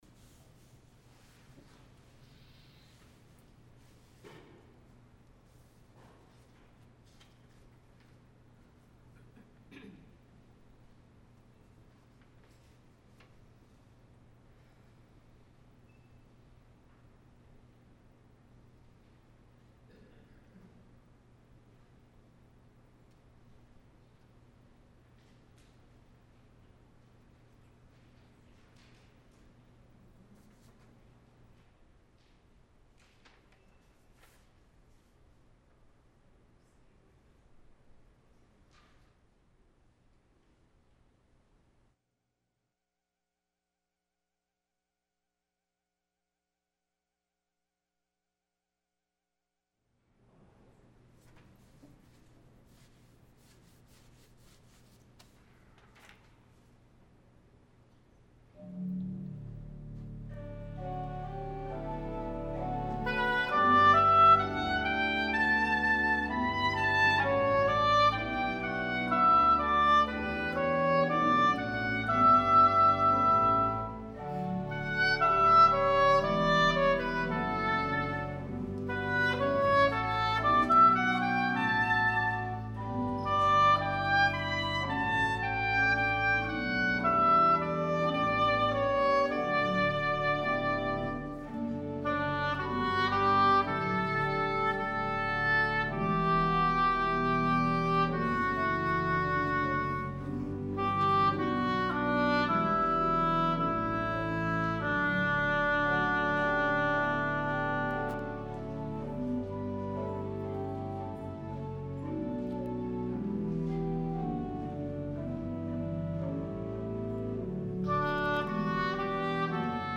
Sunday Worship 12-6-20 (Second Sunday in Advent)